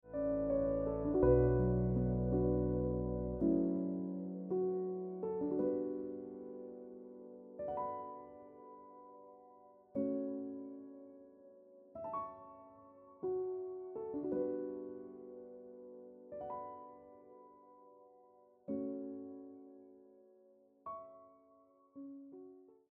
Best Ringtones, Piano Music Ringtone